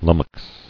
[lum·mox]